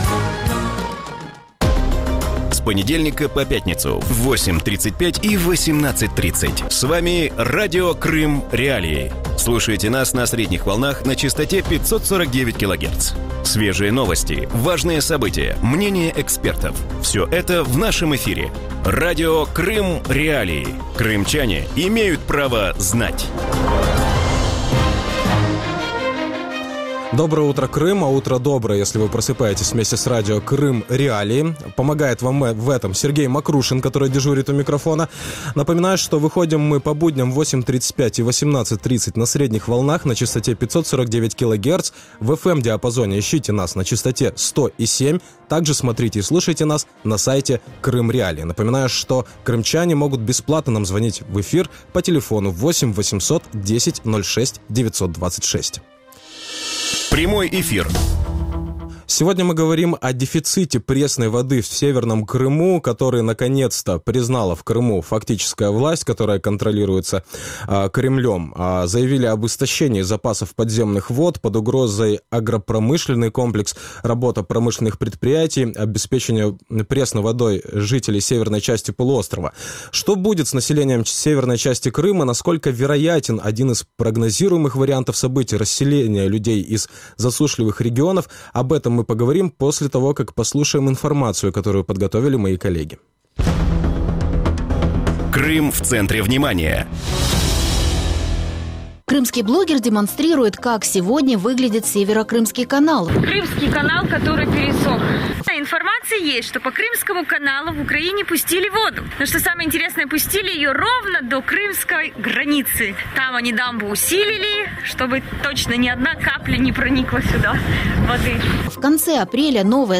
Утром в эфире Радио Крым.Реалии говорят о дефиците пресной воды в Северном Крыму. На полуострове заявили об истощении запасов подземных вод. Под угрозой агропромышленный комплекс, работа промышленных предприятий, а также обеспечение пресной водой жителей северной части Крыма.